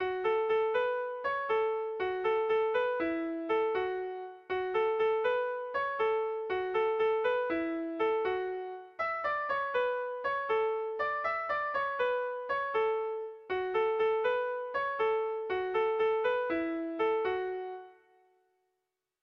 Sentimenduzkoa
Zortziko txikia (hg) / Lau puntuko txikia (ip)
AABA